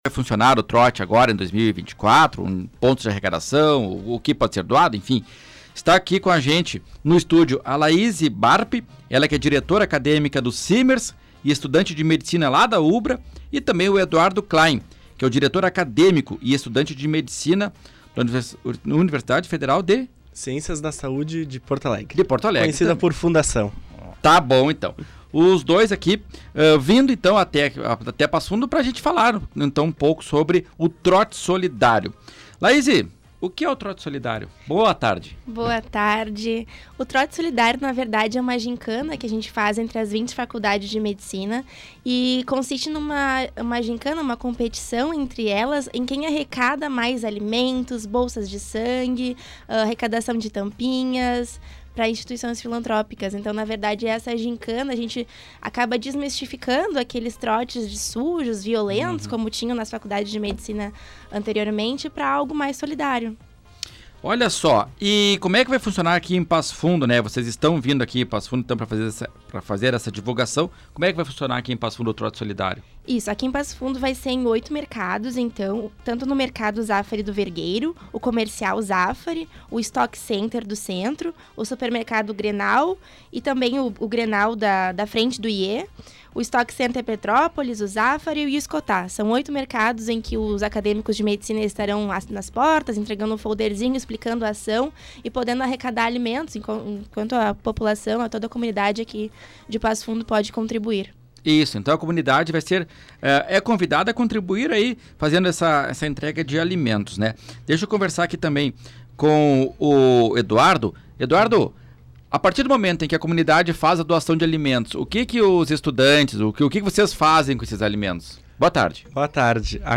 ENTREVISTA-ACADEMICOS-TROTE-SOLIDARIO.mp3